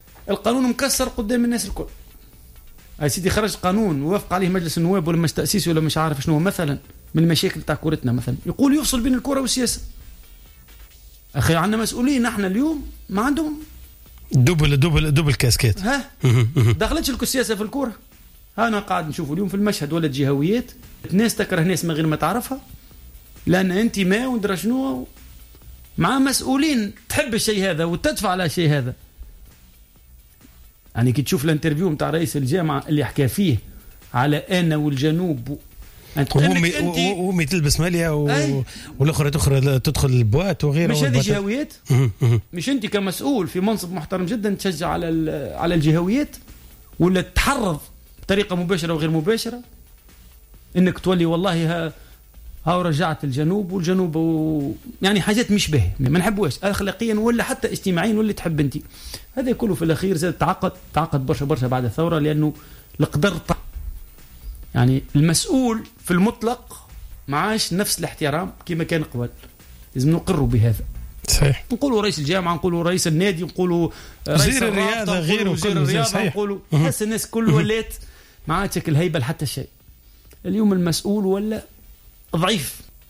أشار ضيف برنامج راديو سبور اللاعب الدولي السابق زبير بية أن من جملة المشاكل التي غرقت فيها كرة القدم التونسية هي المزج بين السياسة و الرياضة بما أن بعض المسؤولين عن النوادي يلعبون دورا مزدوج و الذي خلف العديد من الإشكاليات منها خلق نعرات الجهوية بين الفرق.